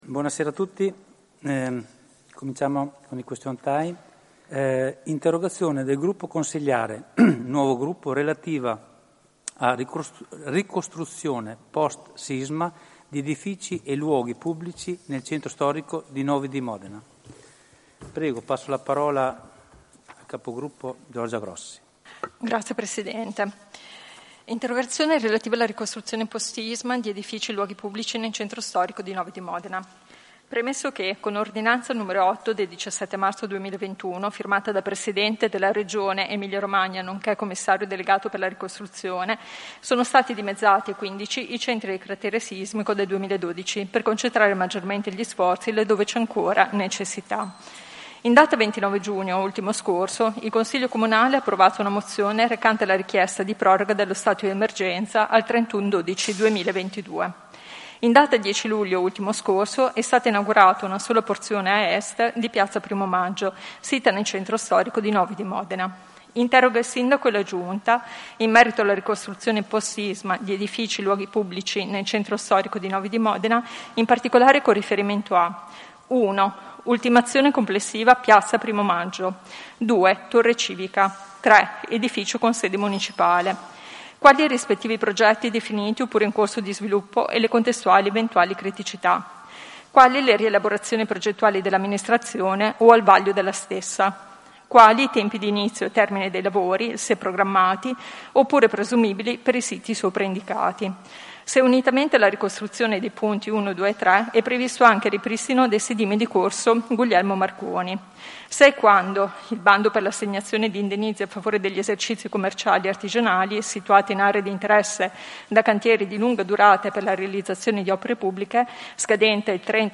Seduta del 29/07/2021